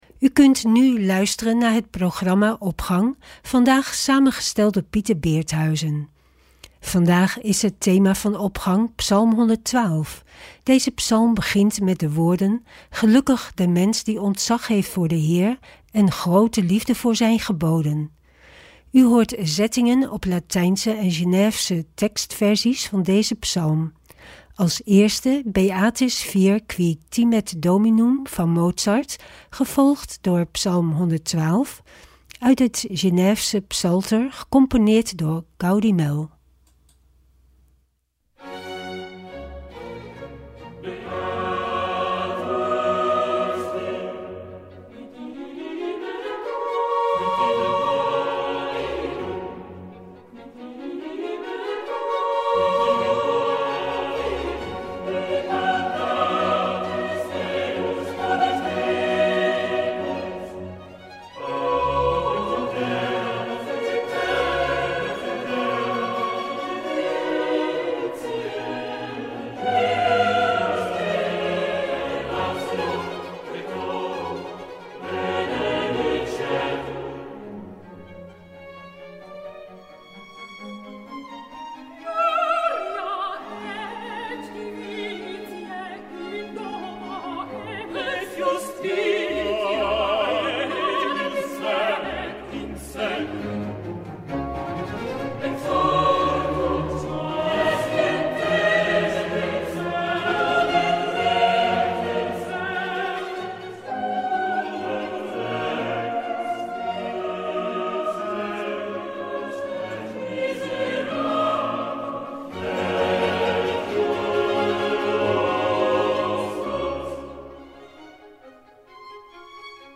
Opening van deze zondag met muziek, rechtstreeks vanuit onze studio.
U hoort zettingen op latijnse en Geneefse tekstversies van deze psalm.